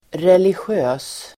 Uttal: [relisj'ö:s]